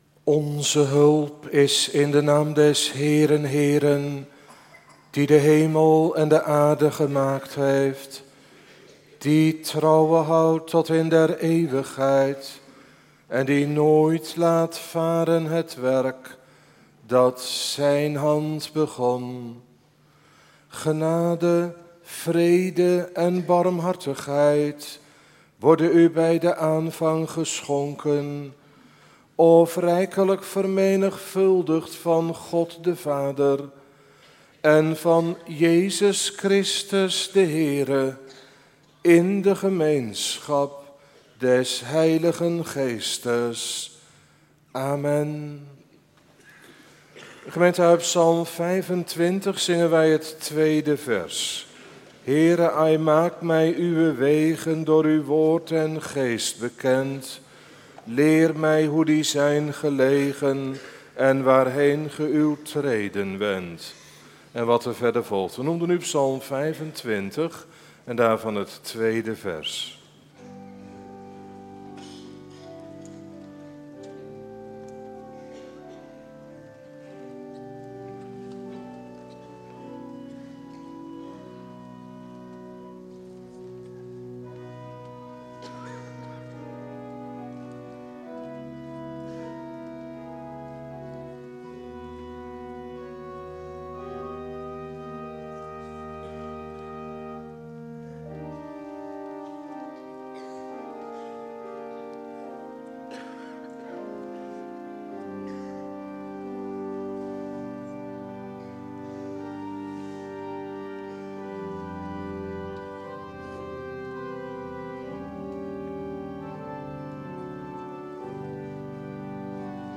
Prekenarchief